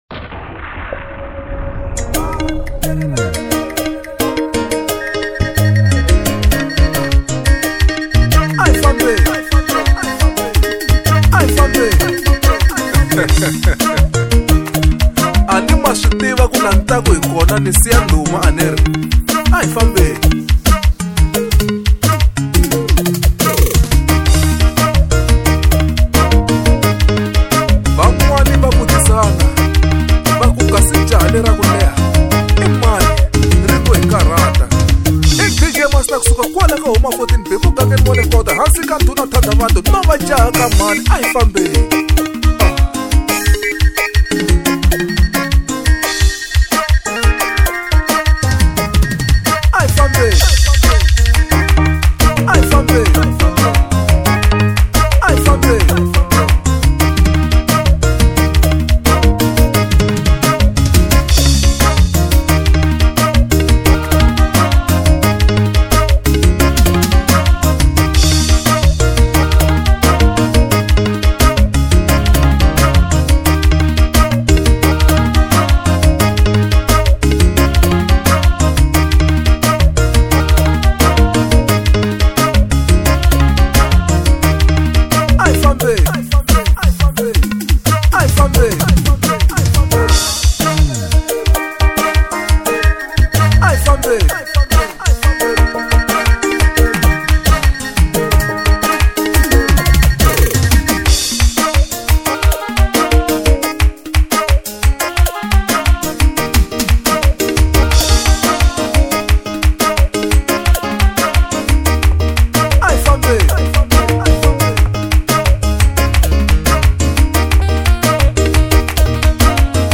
05:20 Genre : Xitsonga Size